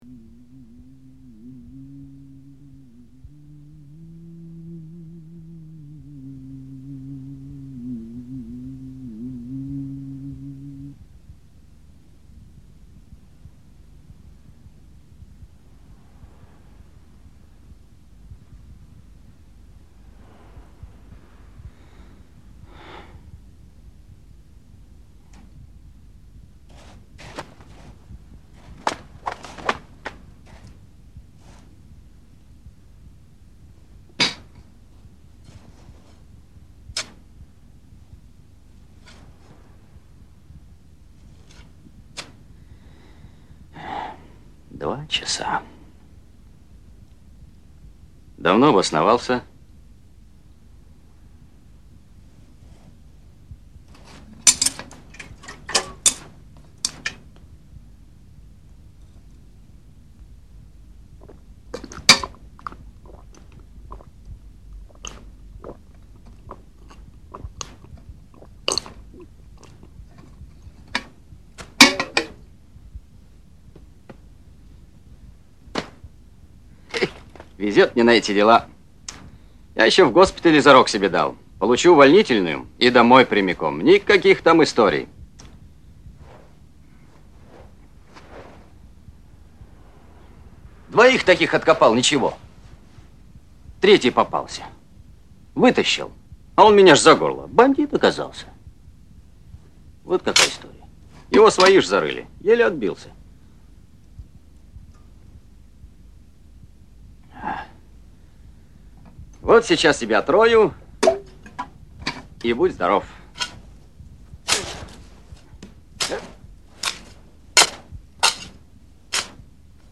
да там и музыкальное сопровождение из фильма :).